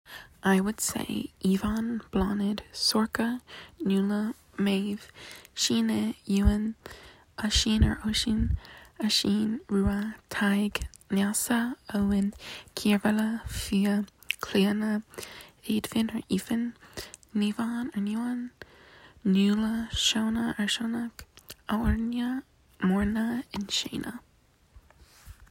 I’ll try to do my best to explain these as a non-Gaeilge speaker! I attached a voice note to hopefully make it more clear.
Bláthnaid: blaw-nid
Cíormheala: keer-vah-lah
Clíodhna: klee-uh-na
Naomhán: neev-awn/nee-wan
Aurnia: ah-ore-nee-ah (with an almost trilled R, which I can’t do)
Muirne: mor-na